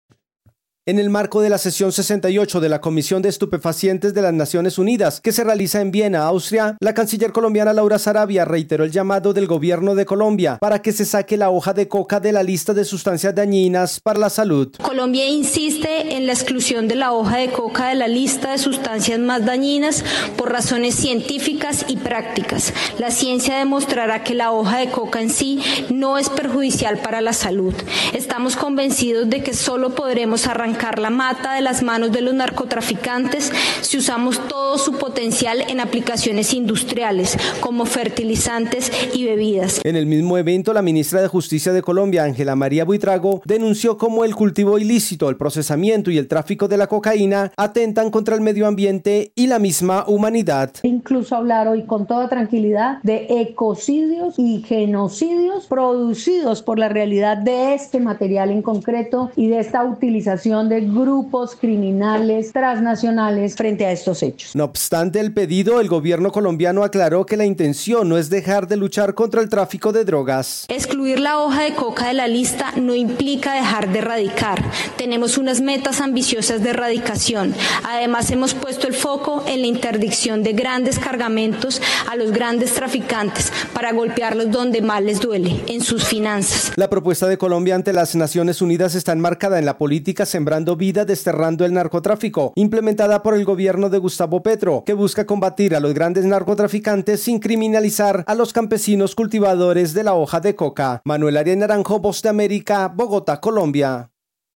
Colombia insistió ante la Comisión de Estupefacientes de las Naciones Unidas en la legalización y comercialización de la hoja de coca. Desde Colombia informa el corresponsal de la Voz de América